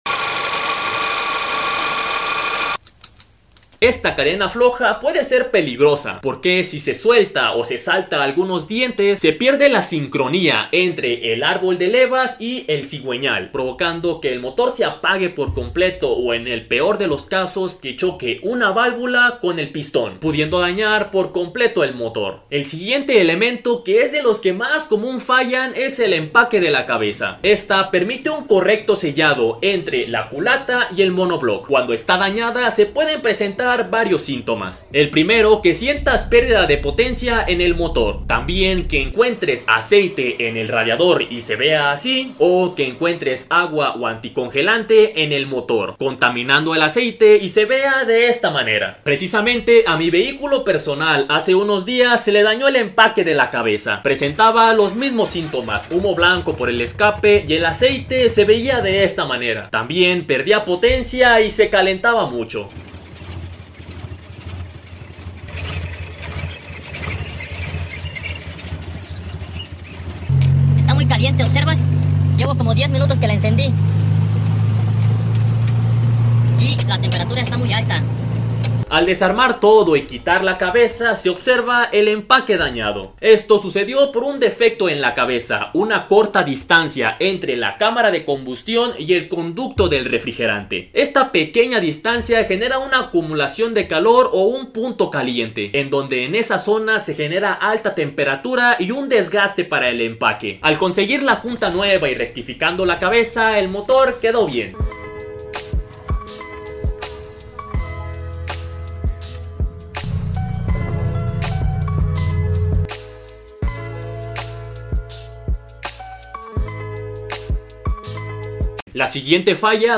TODOS LOS RUIDOS del MOTOR sound effects free download
TODOS LOS RUIDOS del MOTOR DAÑADO / VIBRACIÓN, SONIDO al ACELERAR, tiembla, se calienta, DESVIELADO?